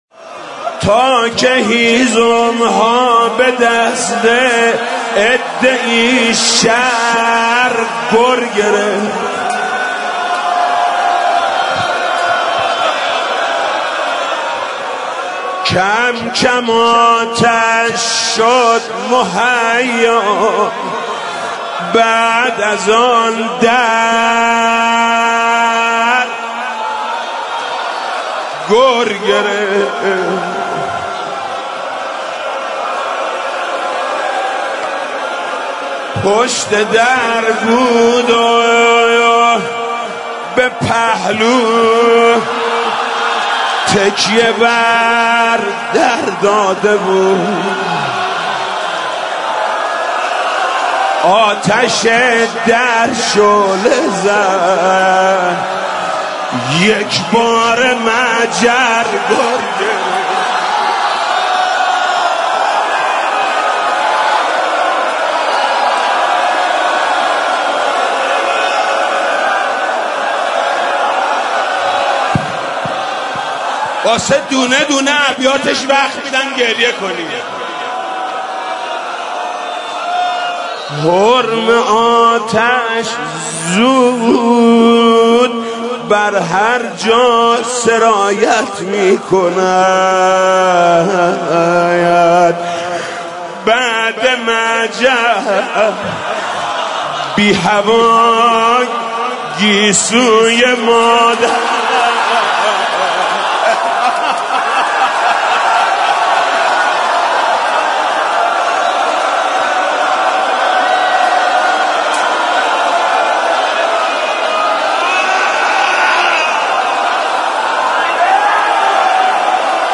صوت مداحی حاج محمود کریمی ایام شهادت حضرت صدیقه طاهره (س) منتشر می شود.